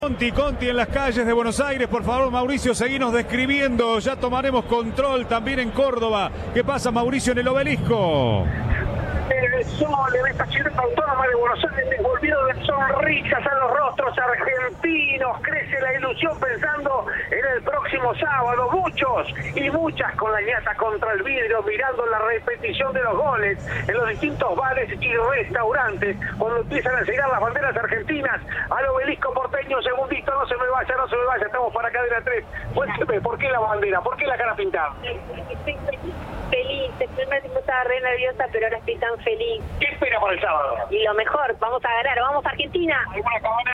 Audio. Festejos en Buenos Aires por el pase de la Selección a octavos de final.